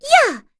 voices / heroes / en
Dosarta-Vox_Skill1-1.wav